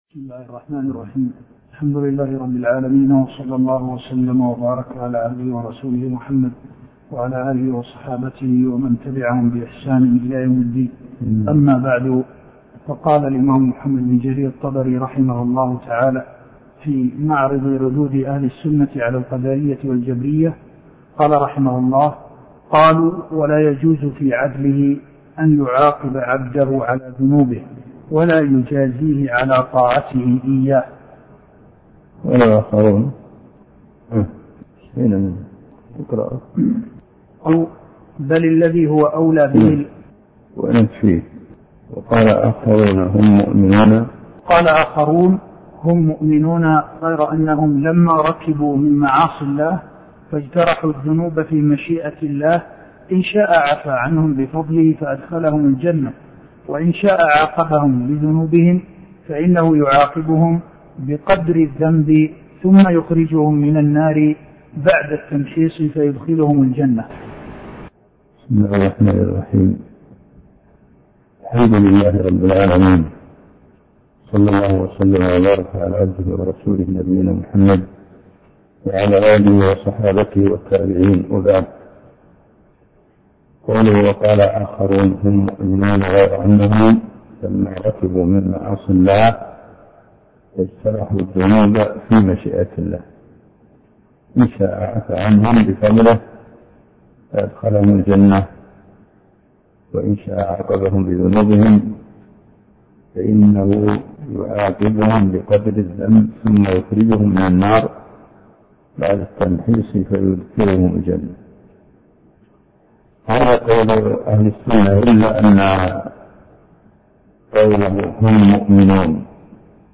عنوان المادة الدرس (7)شرح كتاب التبصير في معالم الدين لابن جرير الطبري تاريخ التحميل السبت 31 ديسمبر 2022 مـ حجم المادة 40.80 ميجا بايت عدد الزيارات 111 زيارة عدد مرات الحفظ 48 مرة إستماع المادة حفظ المادة اضف تعليقك أرسل لصديق